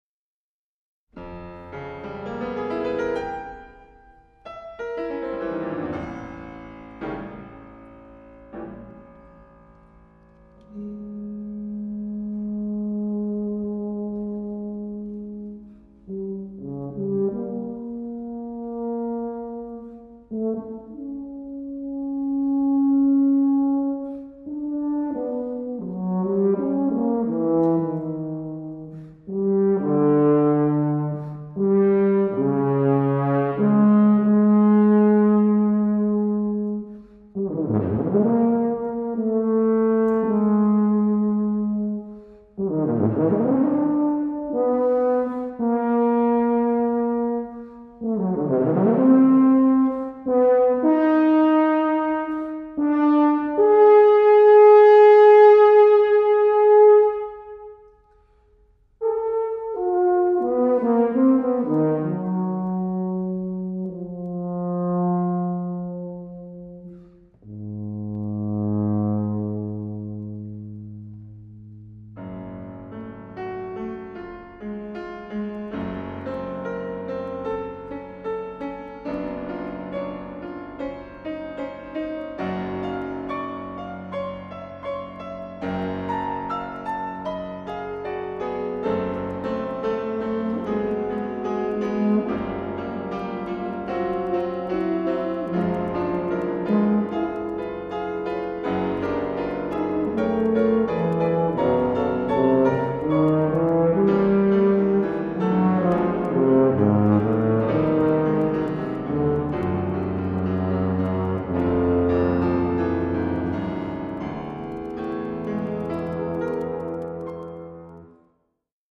Voicing: Tuba